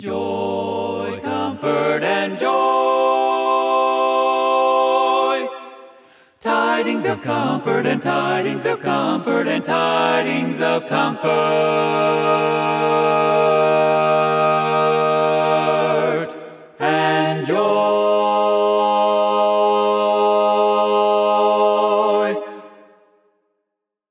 Key written in: G Minor
How many parts: 4
Type: Barbershop
All Parts mix: